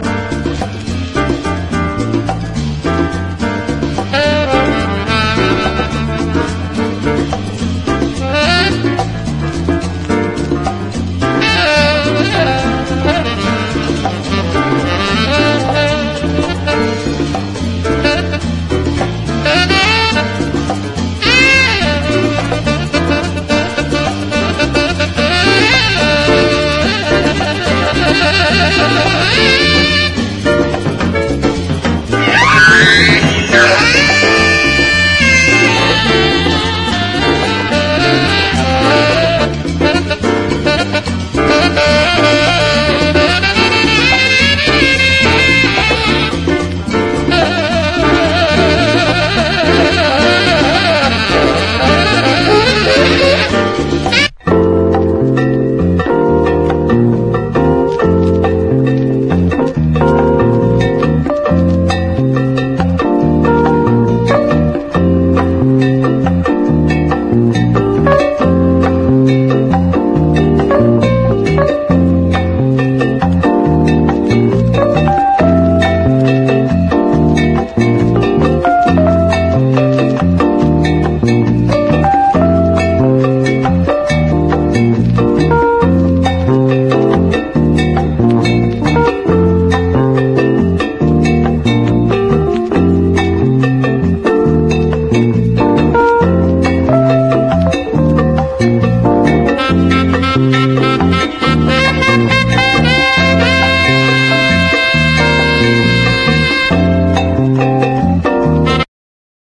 JAZZ / DANCEFLOOR / NEW ORLEANS